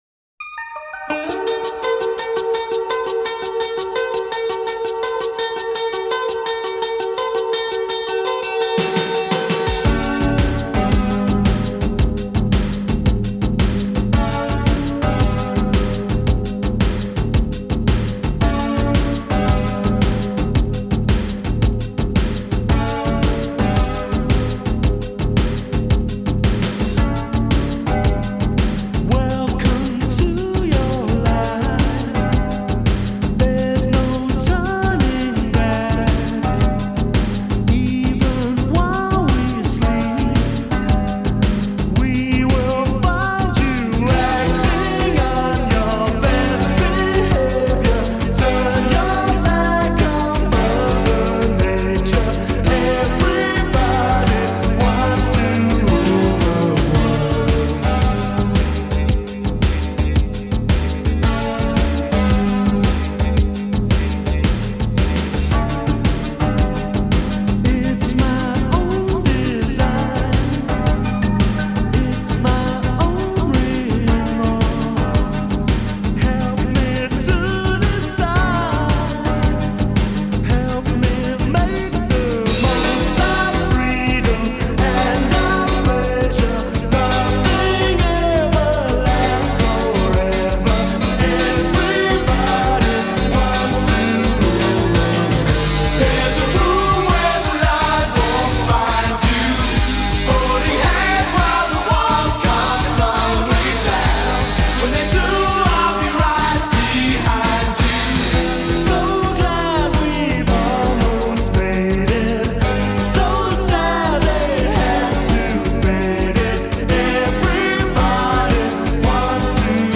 I sometimes make electronic music.
let me take you on a little musical journey.